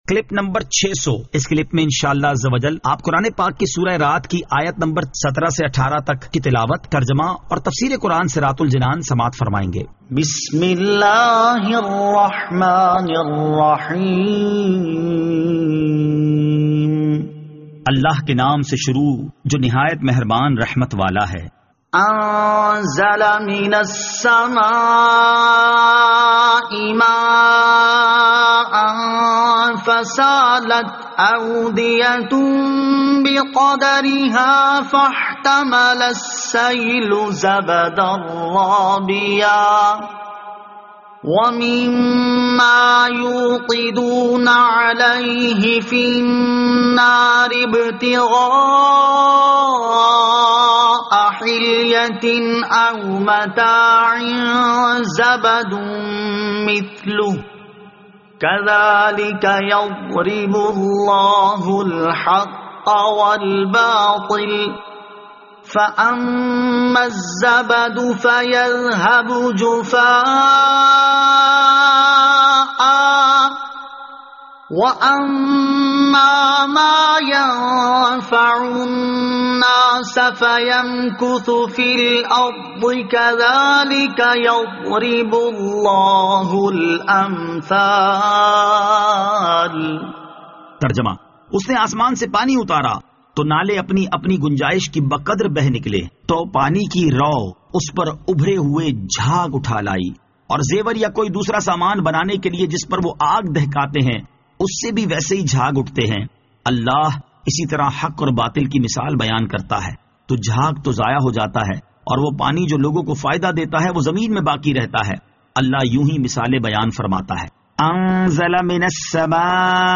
Surah Ar-Rad Ayat 17 To 18 Tilawat , Tarjama , Tafseer